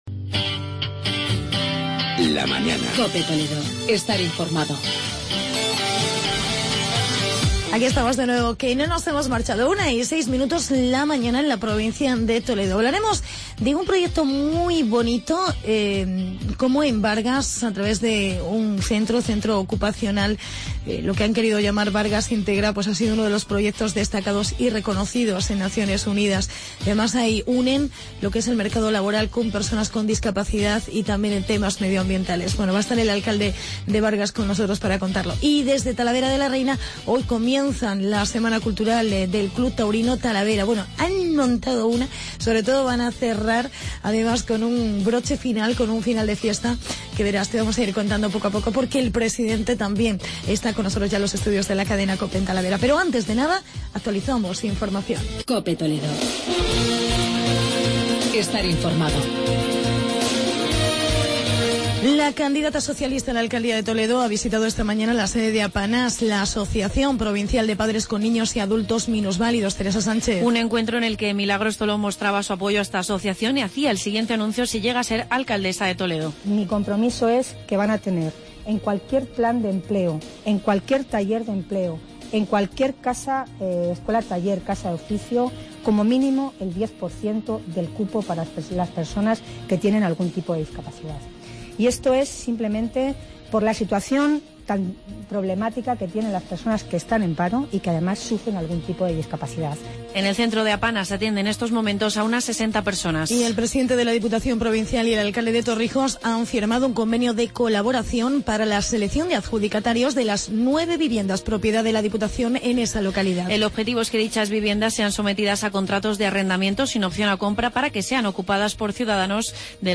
entrevista con el alcalde de Bargas, Gustavo Figueroa